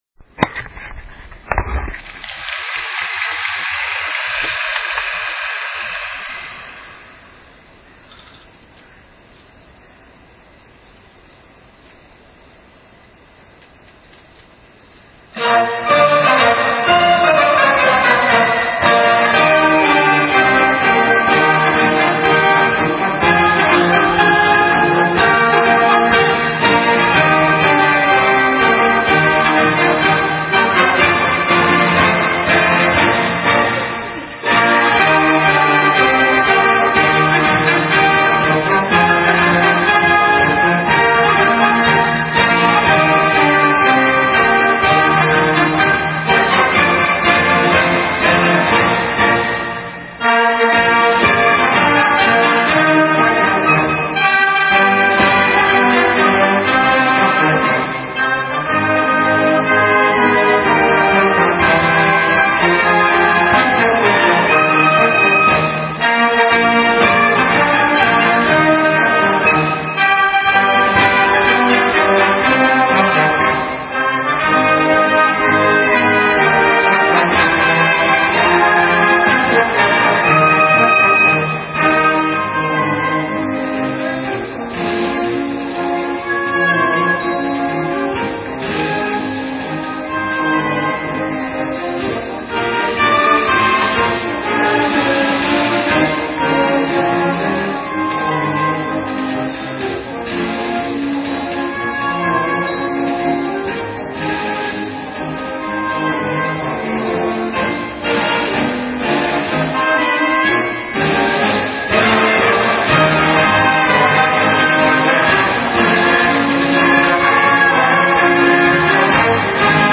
演奏・・・・・・
吹奏楽コンクール.MP3
県吹奏楽コンクール　7/25
中日吹奏楽コンクール　7/28
心ひとつに、吹奏楽部